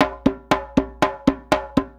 120 JEMBE1.wav